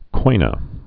(kānə)